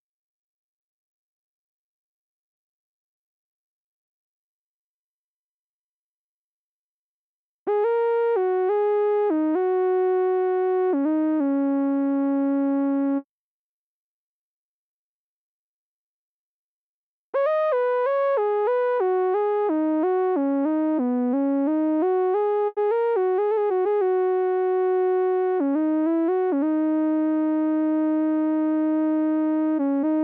14 lead B1.wav